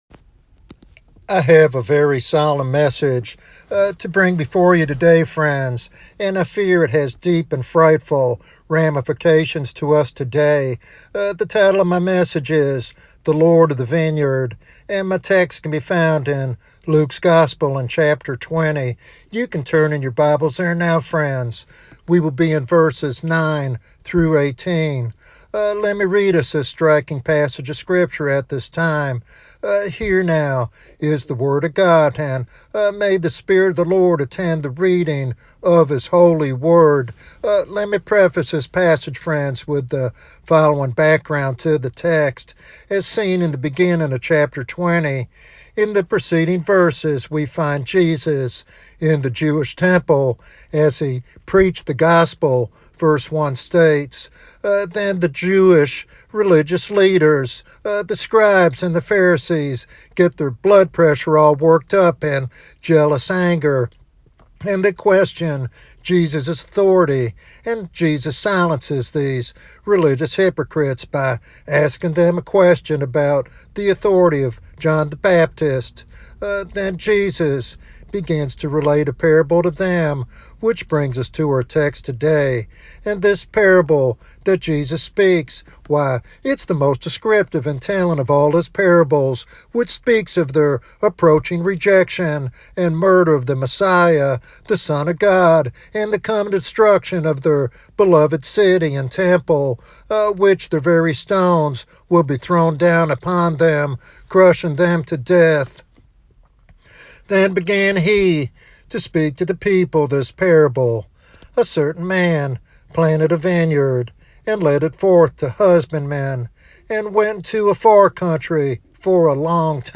This sermon challenges listeners to examine their spiritual condition and respond with urgency.